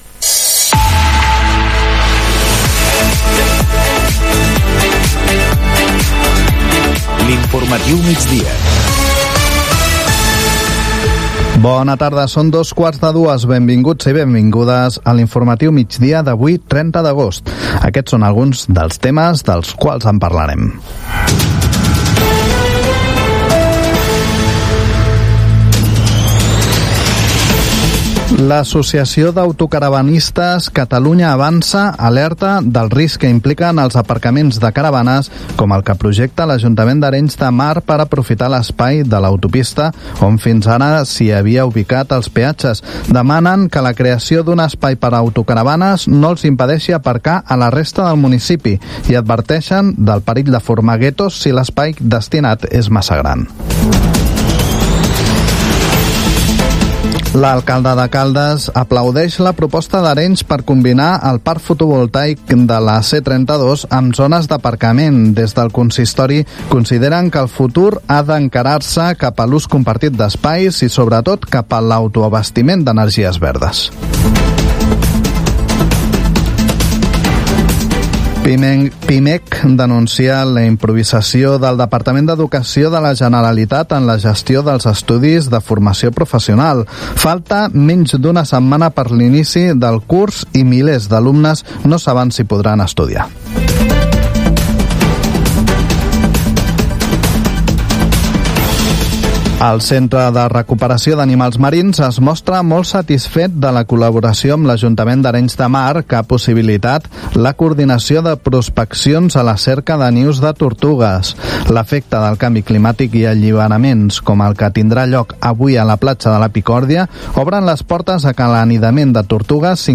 Identificació, data, sumari informatiu, indicatiu del programa
Informatiu
FM